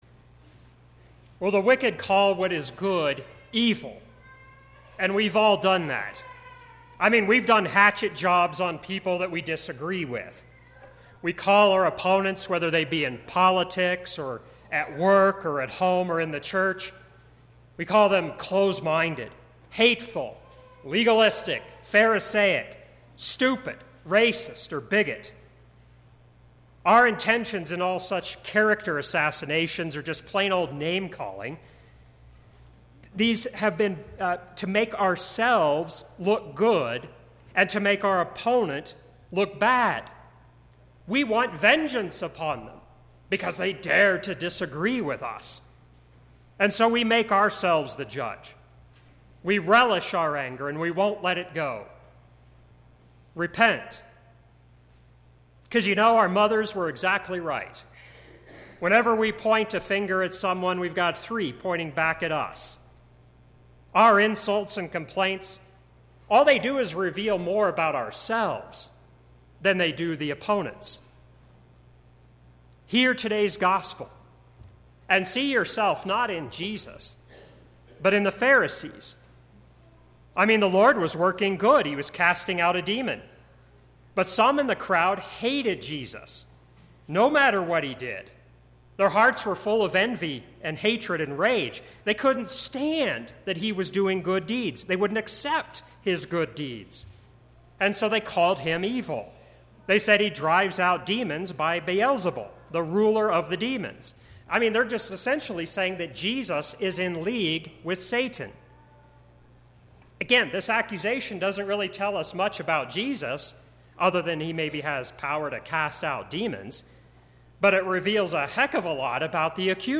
Sermon – 3/19/2017